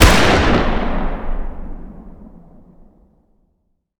fire-dist-44mag-pistol-ext-02.ogg